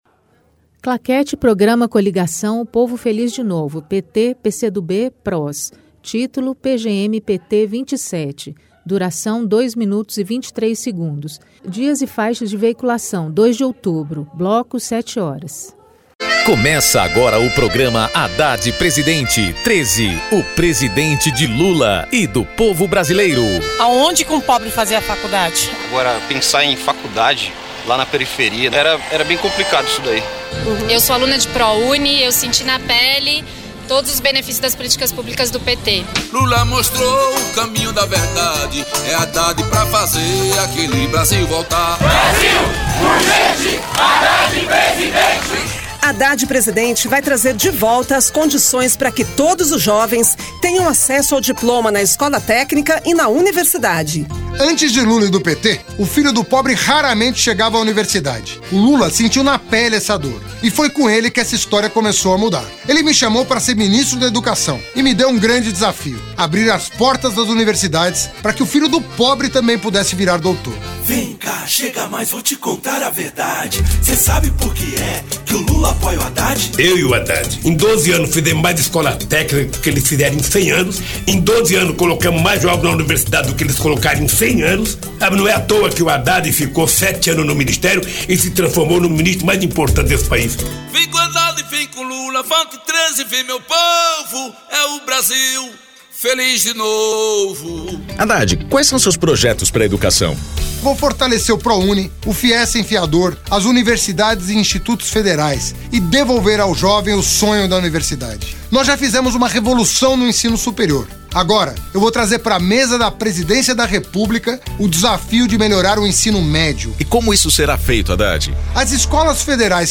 Gênero documentaldocumento sonoro
Descrição Programa de rádio da campanha de 2018 (edição 27) - 1° turno